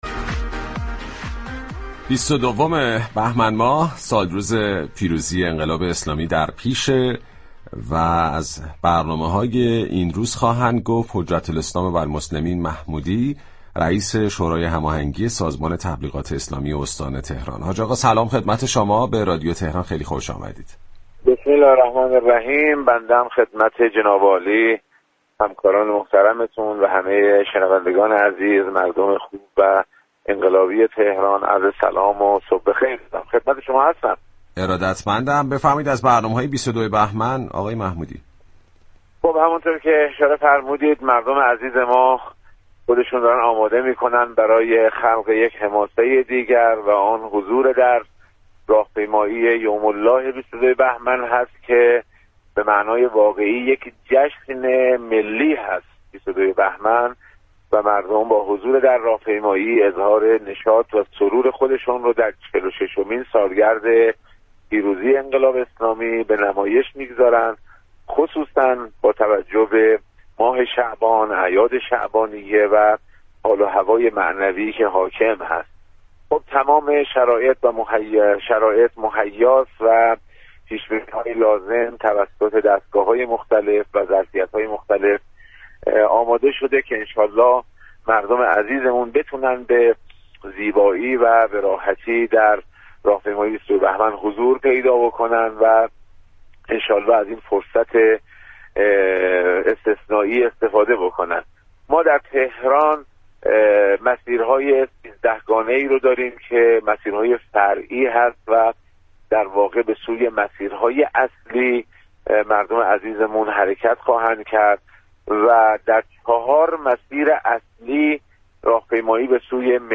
به گزارش پایگاه اطلاع رسانی رادیو تهران؛ حجت الاسلام سید محسن محمودی رئیس شورای هماهنگی تبلیغات اسلامی استان تهران در گفت و گو با رادیو تهران اظهار داشت: مردم ایران با حضور در راهپیمایی یوم الله 22بهمن 1403 به دنبال خلق حماسه ای دیگر هستند.